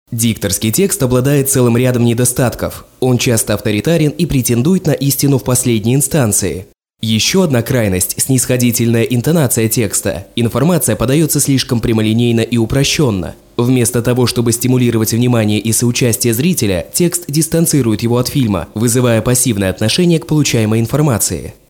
Locutores bielorrusos